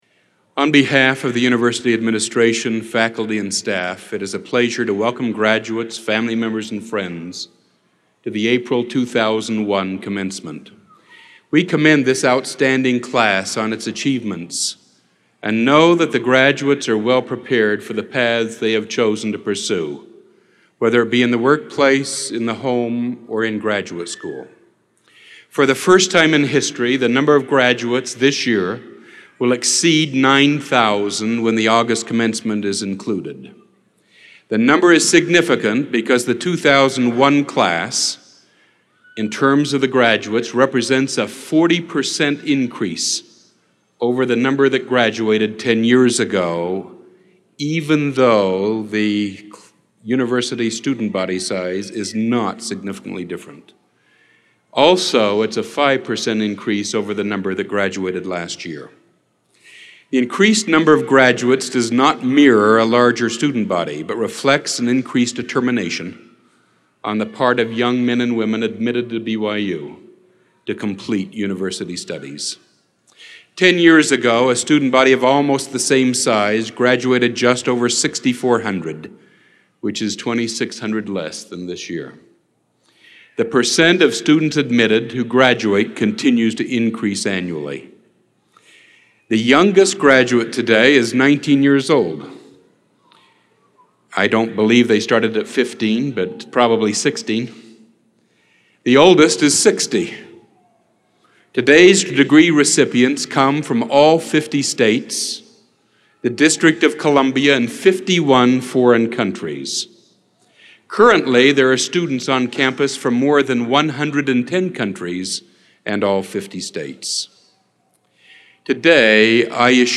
President of Brigham Young University